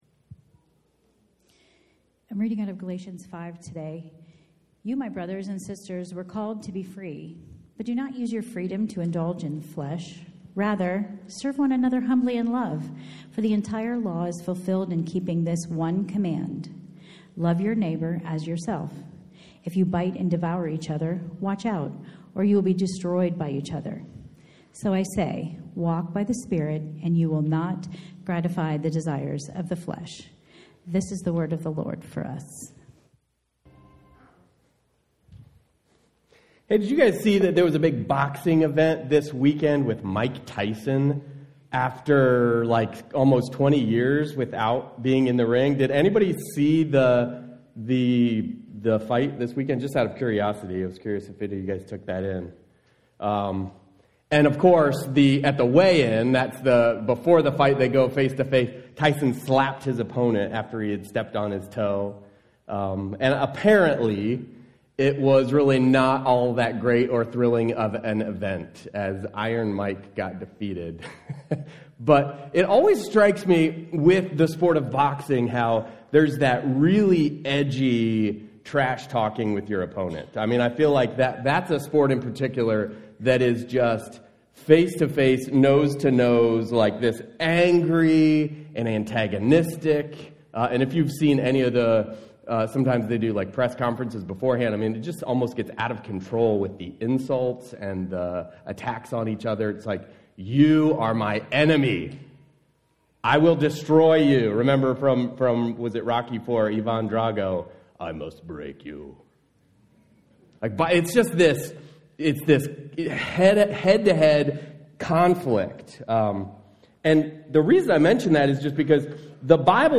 Sermons | Mennonite Christian Assembly